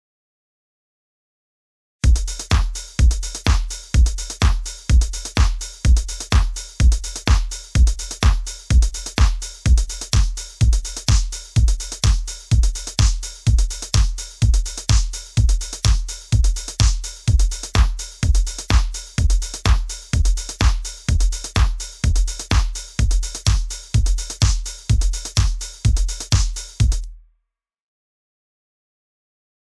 I've attached an audio file with a demonstration starting with it off and then switched on, and switching back and forth. Both EQ, Gate and Compressor as well as Oversampling were disabled when it was switched on. You can hear how it destroys the spring reverb on the snare.